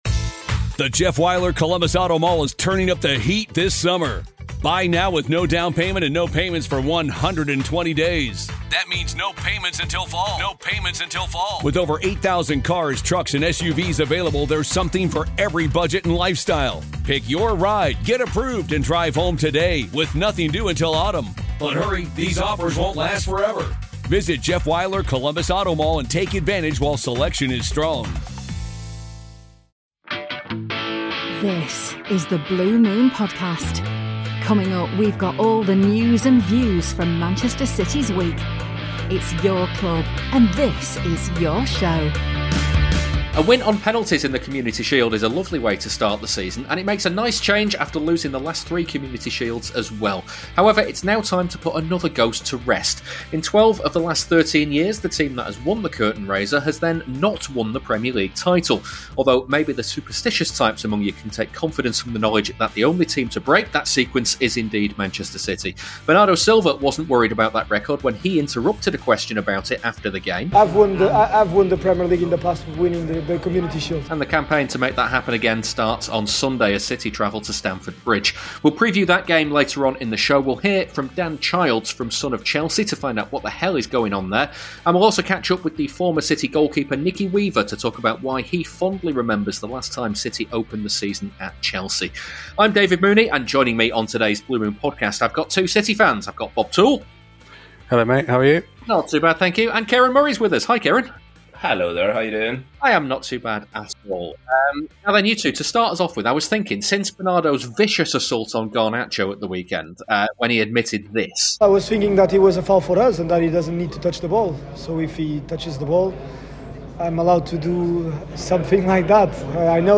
We hear from the former City goalkeeper Nicky Weaver, who tells us why he fondly remembers a 3-0 defeat at Chelsea on the opening day of the 2006-07 season.